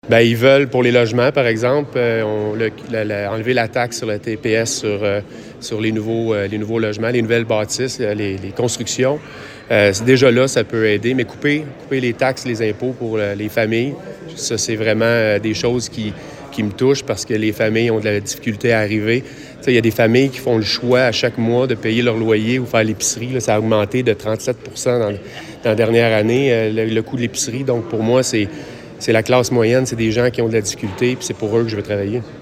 On peut entendre le principal intéressé.